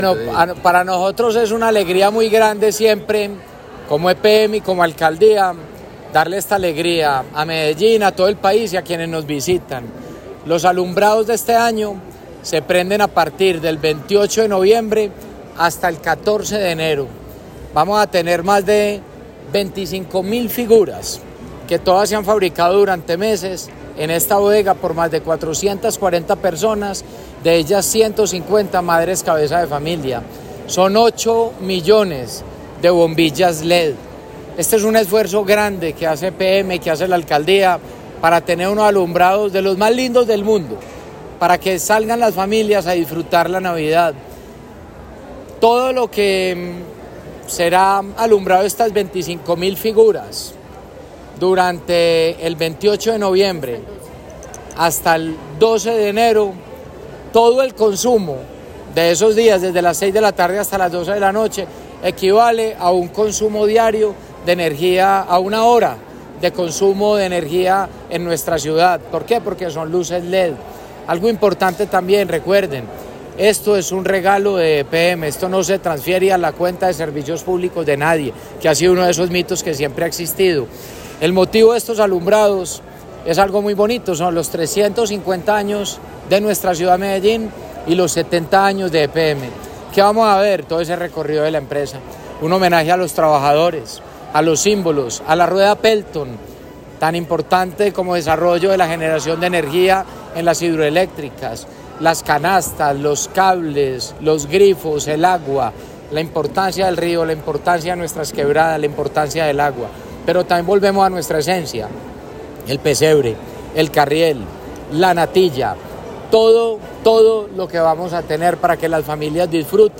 alumbrados2025Alcalde.mp3